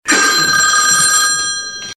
• OLD TELEPHONE RING EFFECT.wav
OLD_TELEPHONE_RING_EFFECT_Bzp.wav